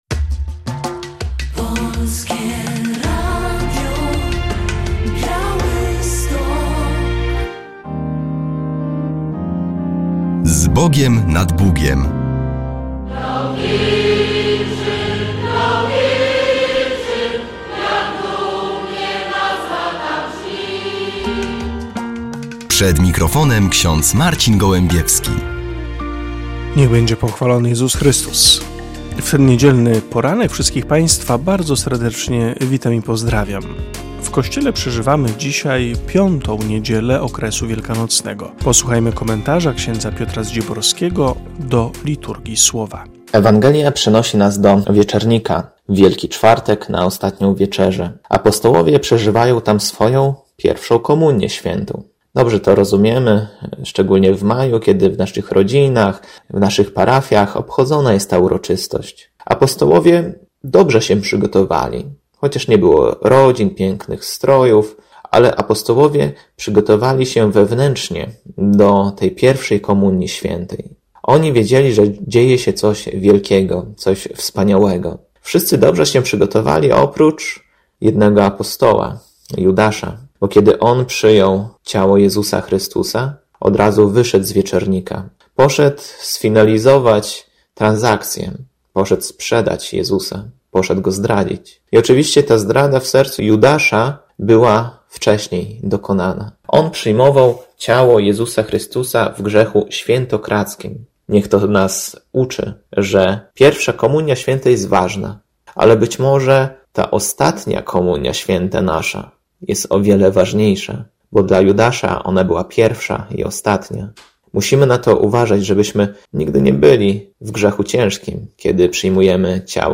W audycji relacja z pierwszego w tym roku nabożeństwa fatimskiego w węgrowskiej bazylice, któremu przewodniczył bp Piotr Sawczuk.